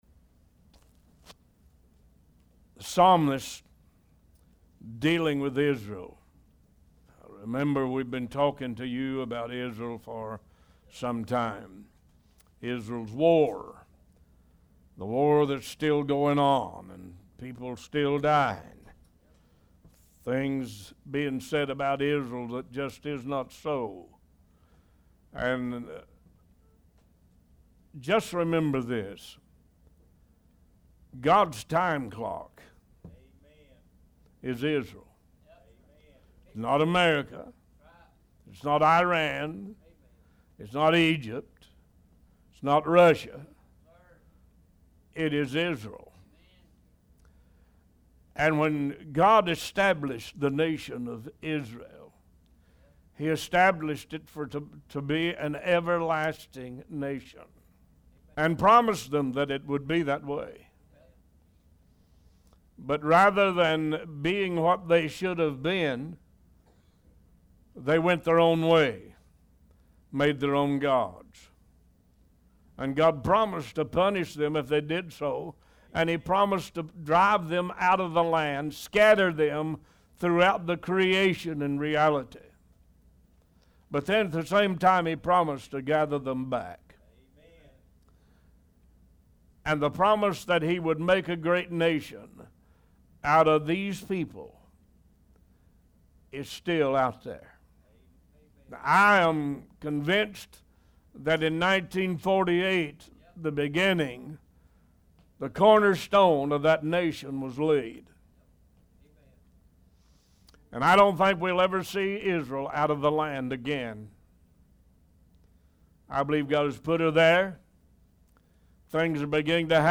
Bible Study
One Voice Talk Show